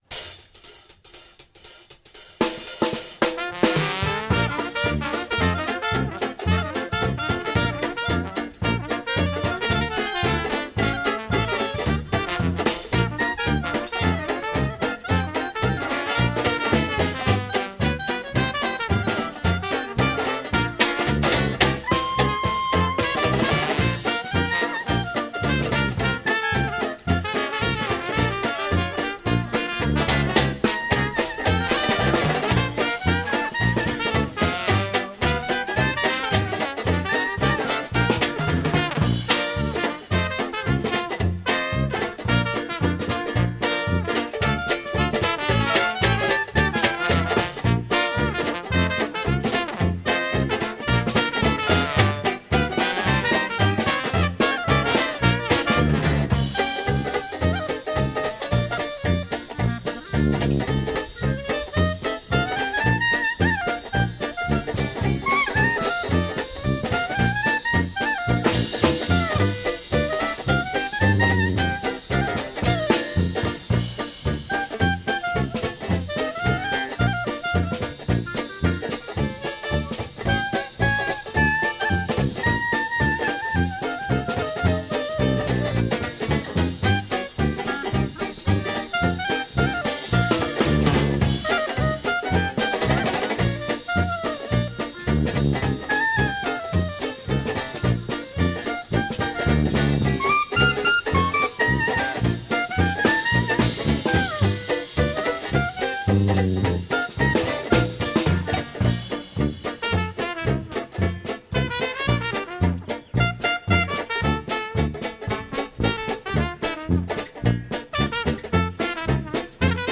klarinet
trobenta
pozavna
tuba
banjo
bobni